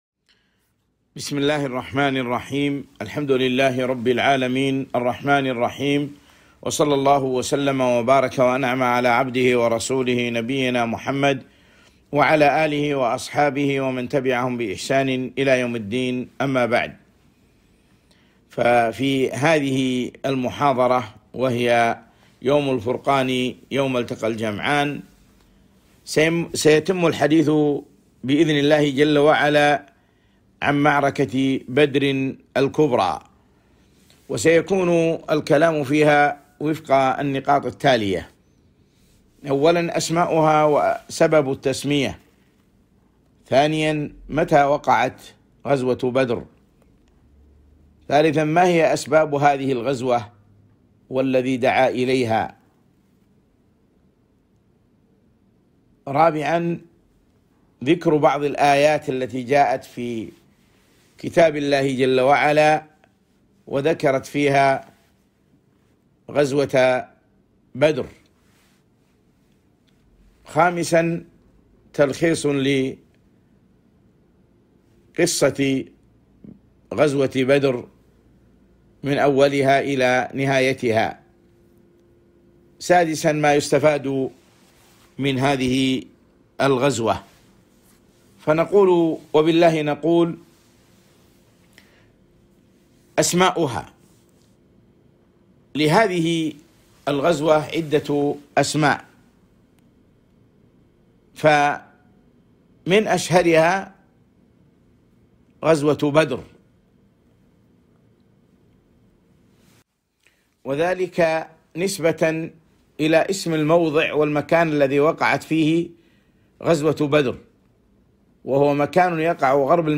محاضرة - غزوة بدر الكبرى